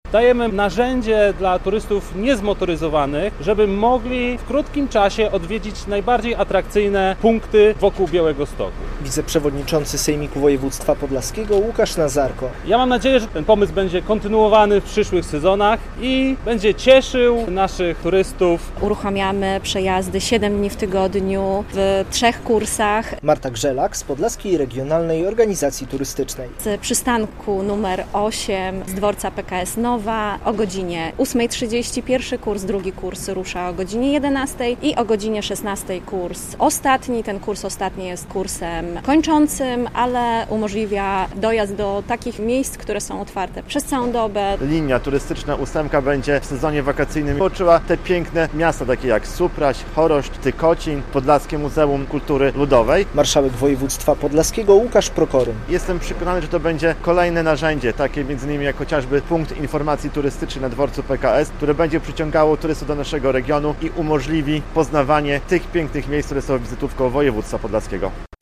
Konferencja prasowa "Turystyczna ósemka", 13.06.2025, fot.
relacja